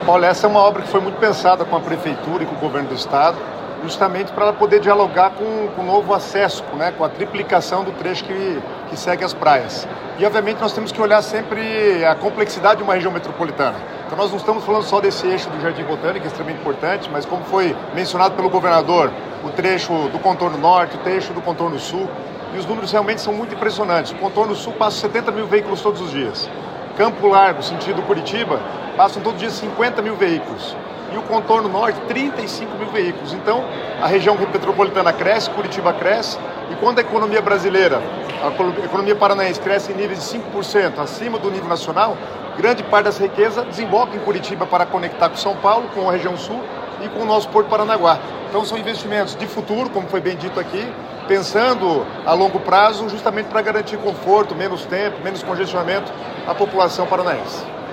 Sonora do secretário das Cidades, Guto Silva, sobre o investimento de investimento de R$ 67 milhões em trincheira no Jardim Botânico | Governo do Estado do Paraná